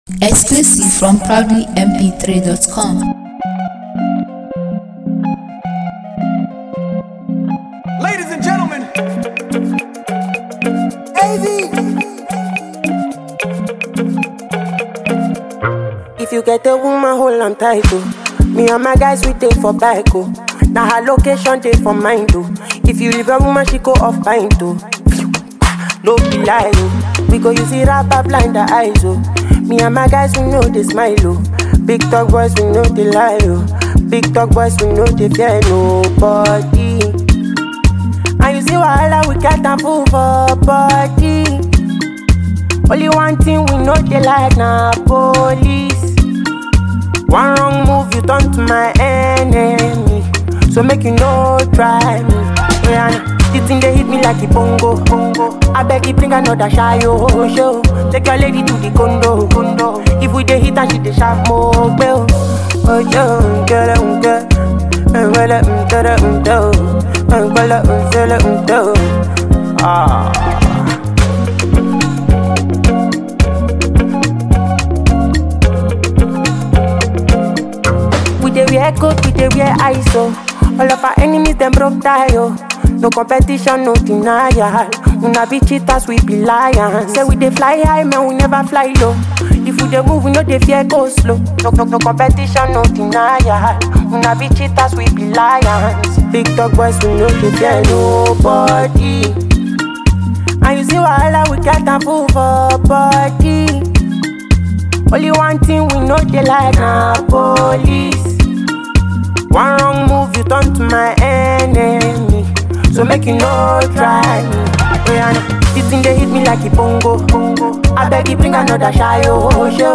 a melodious song fused with a ditty chorus and verse